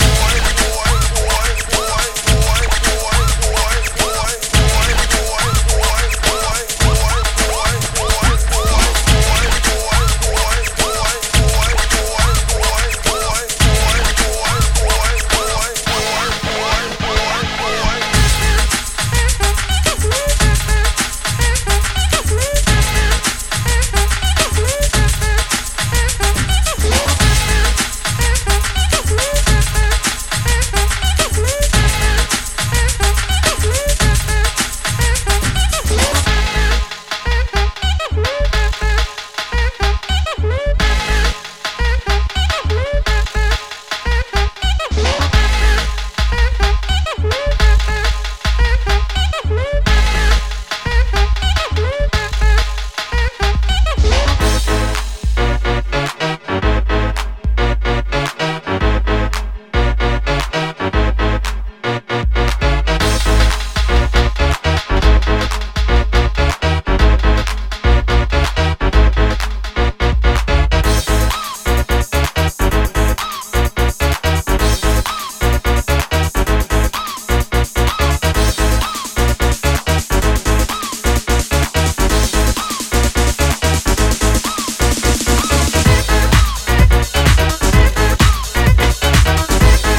ジャンル(スタイル) DEEP HOUSE / HOUSE / TECHNO